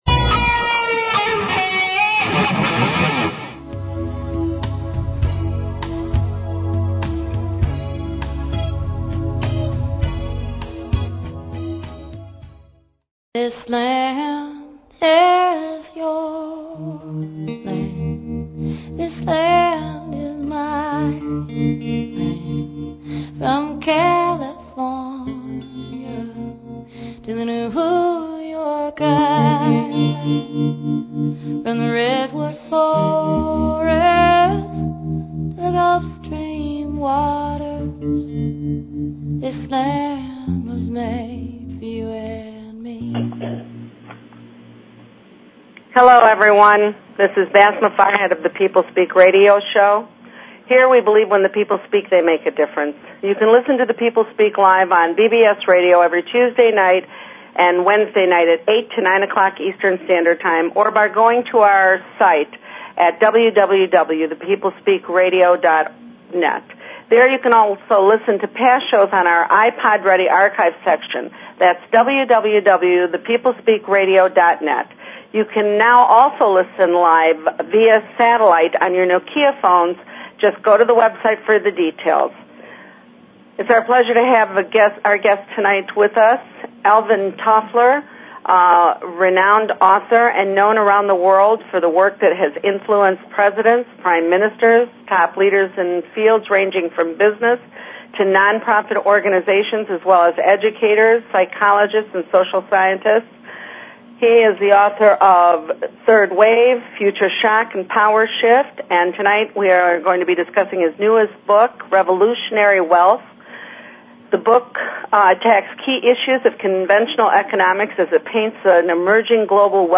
Guest, Alvin Toffler